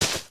sounds / material / human / step / grass01.ogg
grass01.ogg